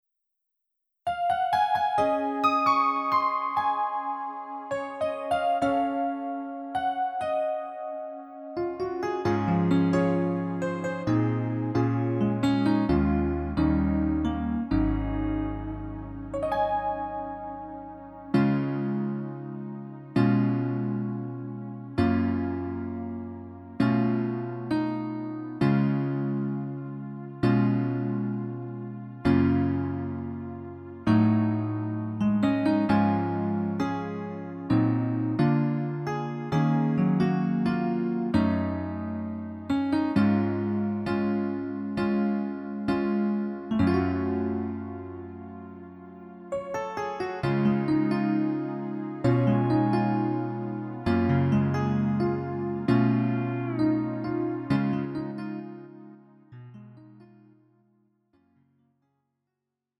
음정 원키 4:28
장르 가요 구분 Lite MR